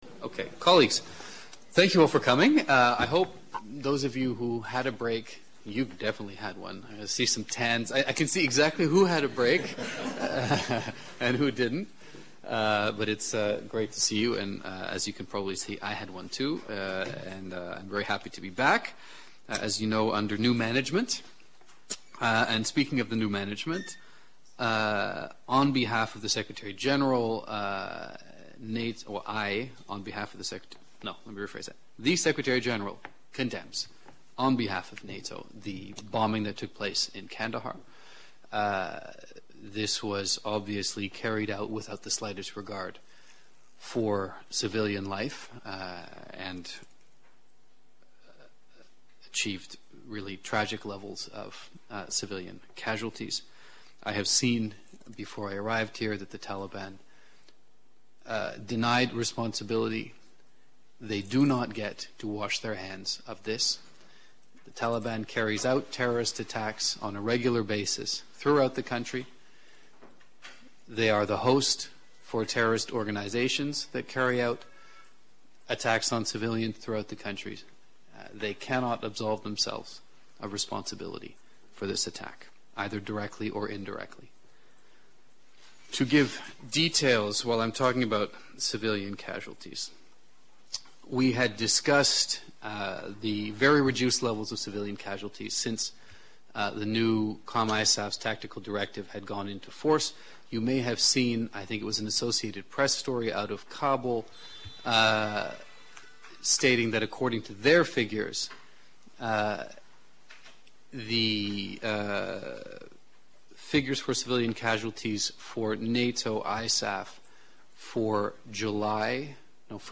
Weekly press briefing